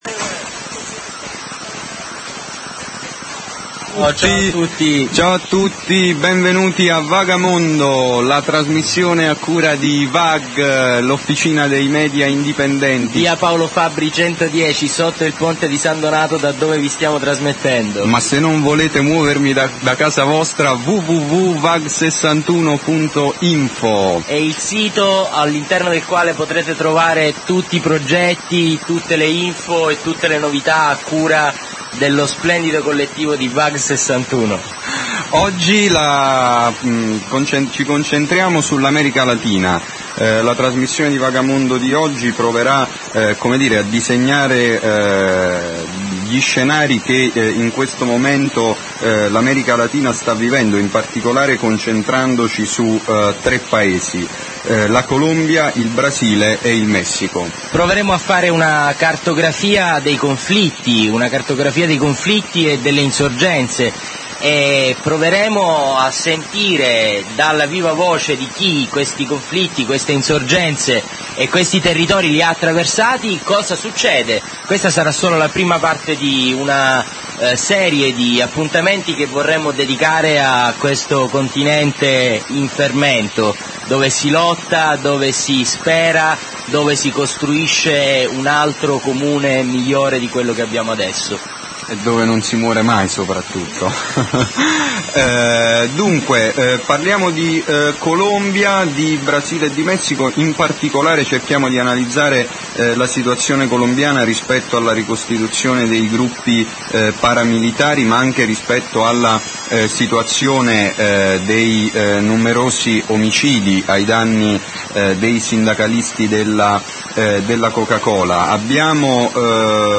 Vag61 - Scarica la puntata del 4 novembre '06 - Sabato 4/11 il primo appuntamento con le narrazioni dalla viva voce di chi ha attraversato i territori e i conflitti di un continente in fermento. La resistenza colombiana allo strapotere Coca-Cola, il Brasile travolto dalla campagna elettorale, le lotte della comune Oaxaca in Messico.